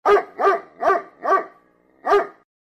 K9 Bark Sound Effect Free Download
K9 Bark